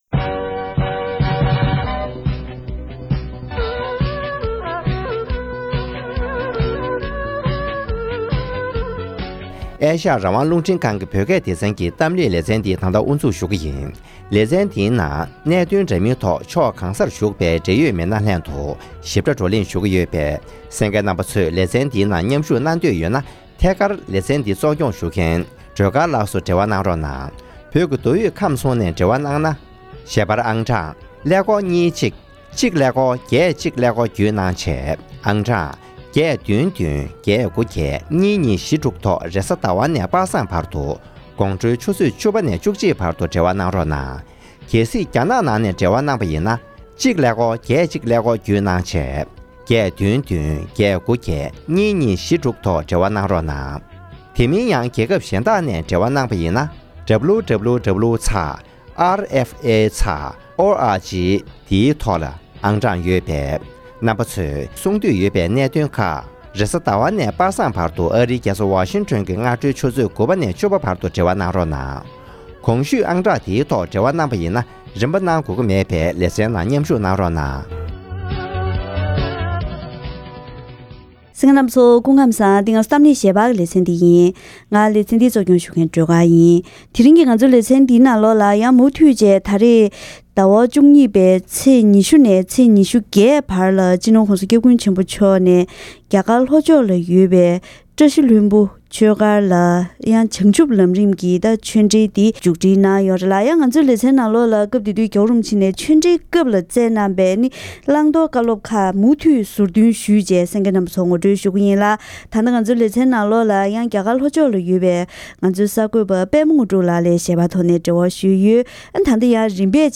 ༄༅། །དེ་རིང་གི་གཏམ་གླེང་ཞལ་པར་ལེ་ཚན་ནང་བྱེས་ཀྱི་བཀྲ་ཤིས་ལྷུན་པོ་དགོན་པར་བྱང་ཆུབ་ལམ་རིམ་གྱི་གསུང་ཆོས་སྐབས་སྤྱི་ནོར་༧གོང་ས་༧སྐྱབས་མགོན་ཆེན་པོ་མཆོག་ནས་ཆོས་ལུགས་མཐུན་སྒྲིལ་དང་གཞན་གཅེས་འཛིན། ཆོས་པ་རྫུན་མ་སོགས་ཀྱི་སྐོར་ལ་བཀའ་སློབ་གནང་བ་ཁག་ངོ་སྤྲོད་ཞུས་པ་ཞིག་གསན་རོགས་གནང་།